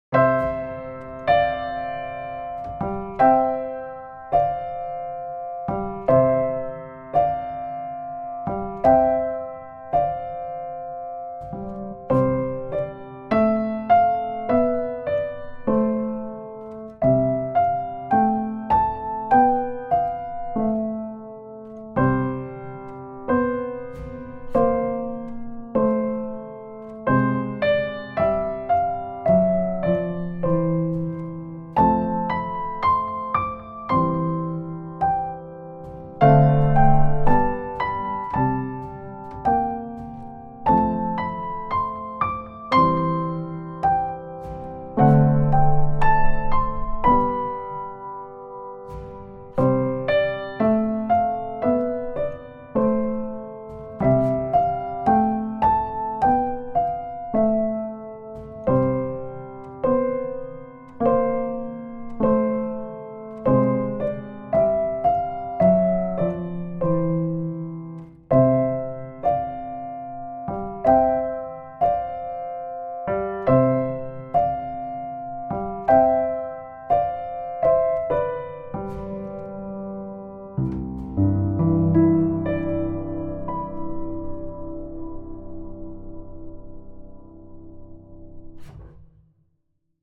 おしゃれ&簡単「カエルのうた」ピアノアレンジ
構成はイントローAーBーAーエンディング。
テンポは７０〜８０くらい。
ジャンルはきれい&ゆったりポップス。
コードは、Cのままで内声を動かしてみました。
Bメロは音域を少し上げて、気分も高めよう